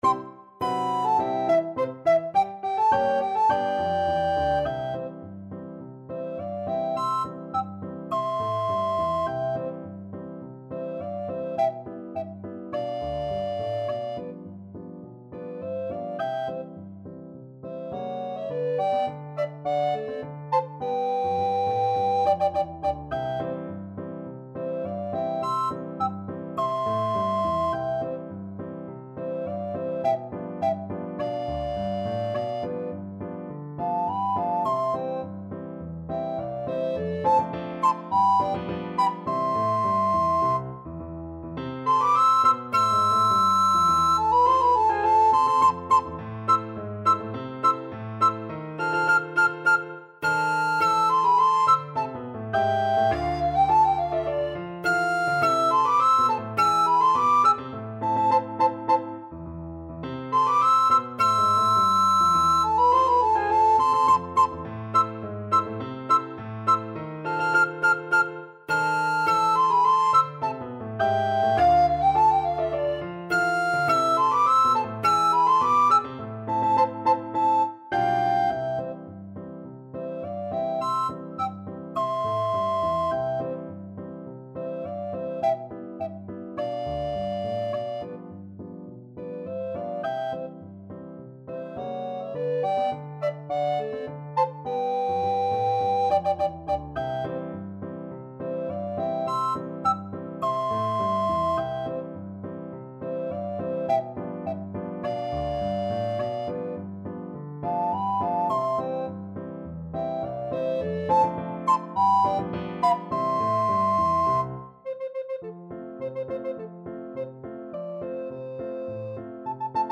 Soprano RecorderAlto Recorder
2/2 (View more 2/2 Music)
Quick March = c.104
Classical (View more Classical Recorder Duet Music)